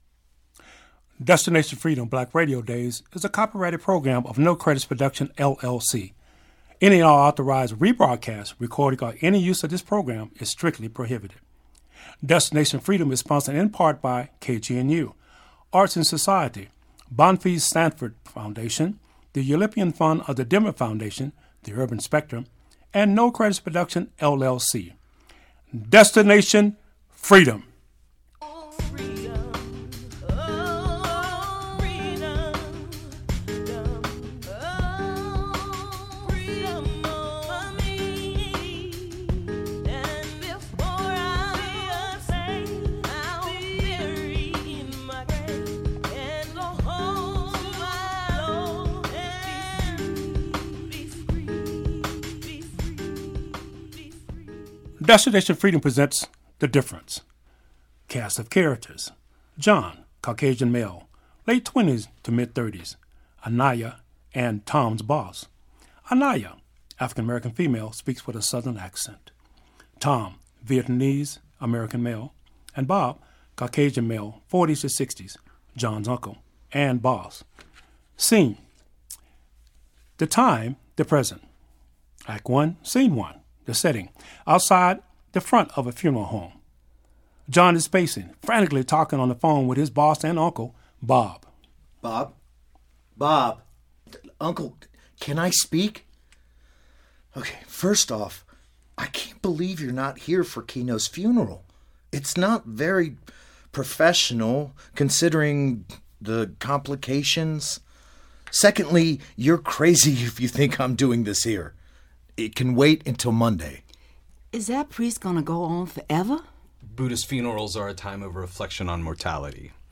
Destination Freedom Black Radio Days broadcasted live from the Boulder studio with their radio play “The Difference.” The show explores racial tensions lurking beneath the workplace and dives into the fear that motivates modern white supremacist ideology.
After the performance is a Q&A with all the cast members.